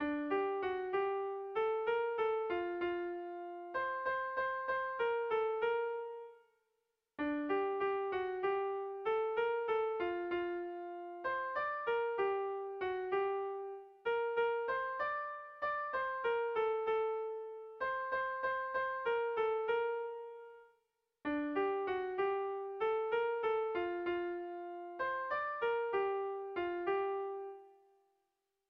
Air de bertsos - Voir fiche   Pour savoir plus sur cette section
Irrizkoa
Zortziko txikia (hg) / Lau puntuko txikia (ip)
A1A2A3A2